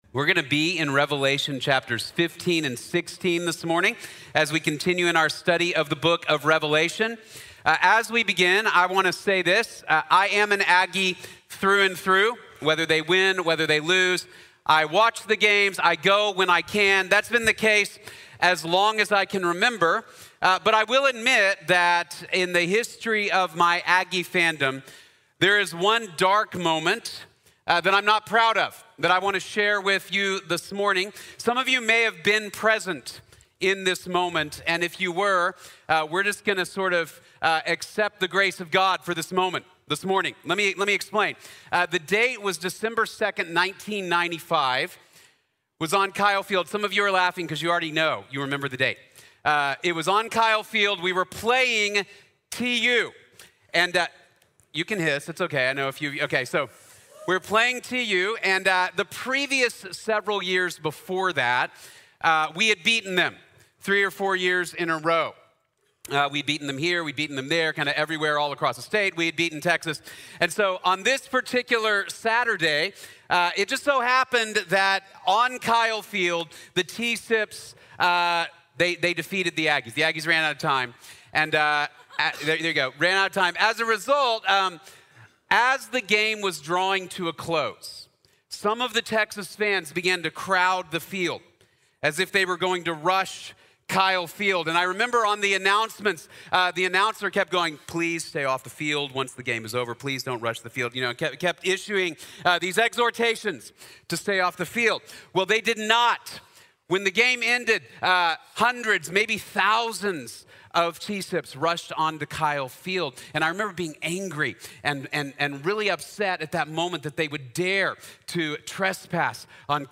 La justicia de Dios es buena | Sermón | Iglesia Bíblica de la Gracia